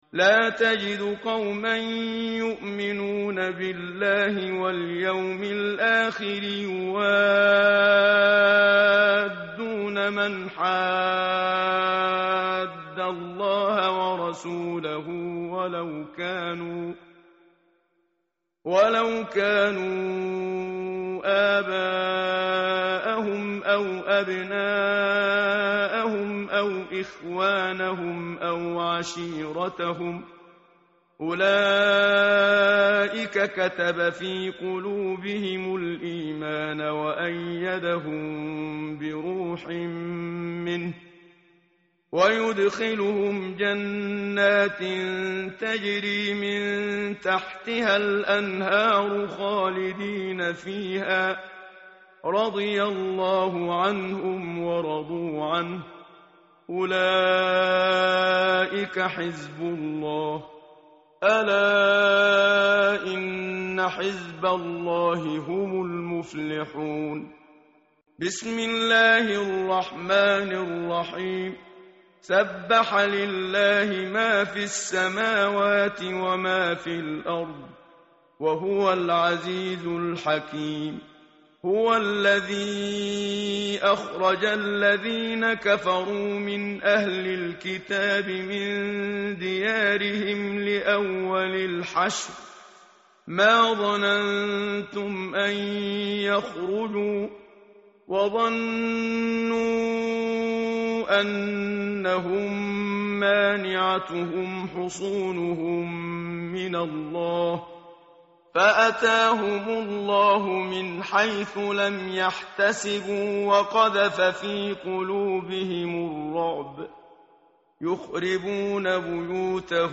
tartil_menshavi_page_545.mp3